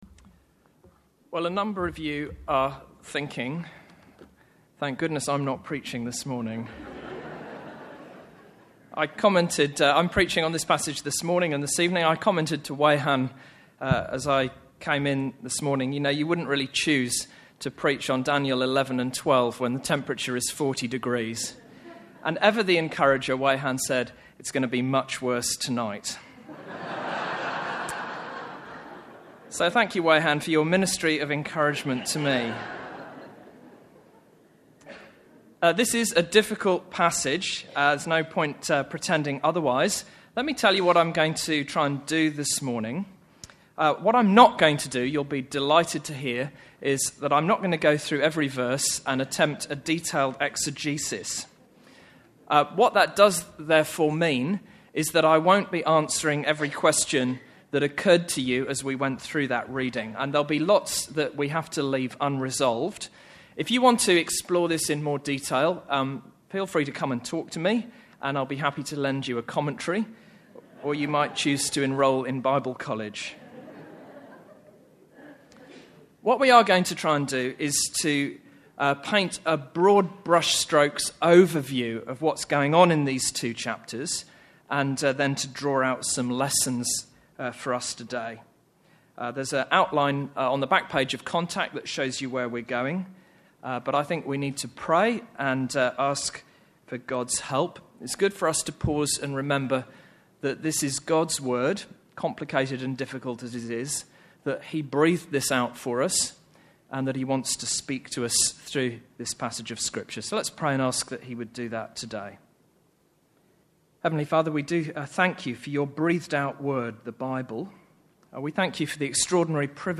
Sermons | St Alfred's Anglican Church